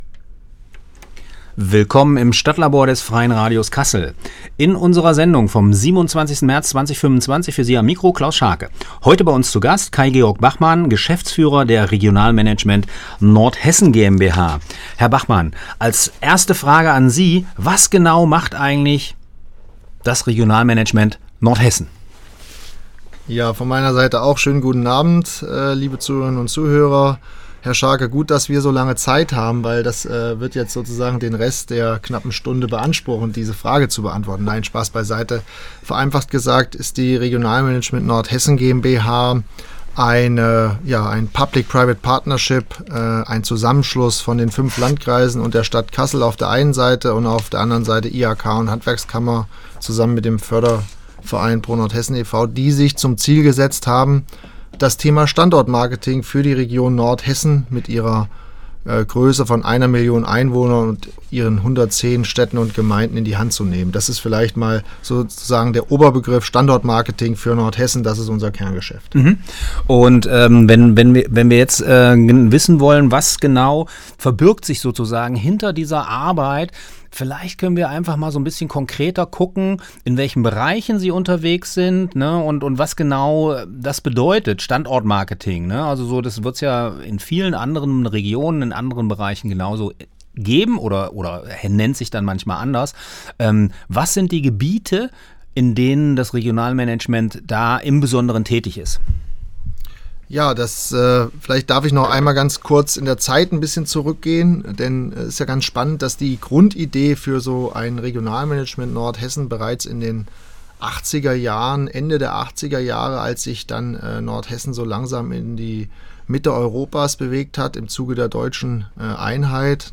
Die Musiktitel sind im Podcast nicht enthalten.